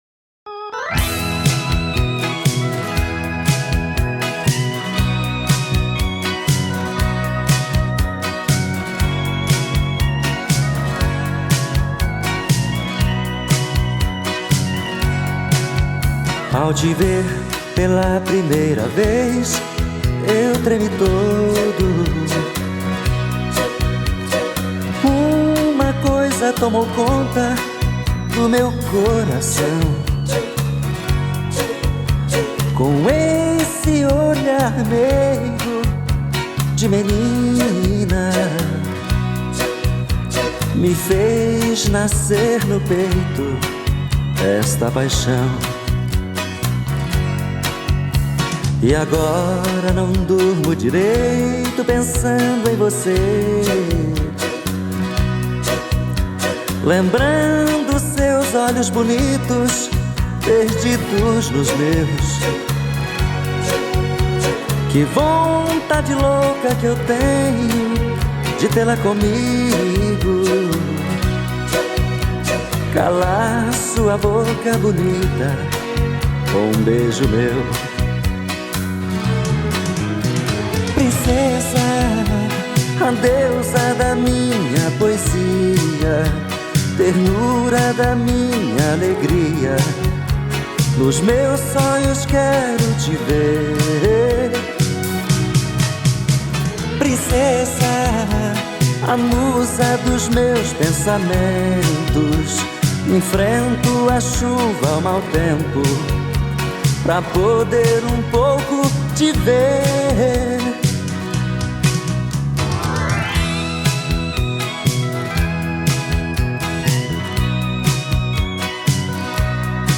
2025-01-03 17:42:47 Gênero: Sertanejo Views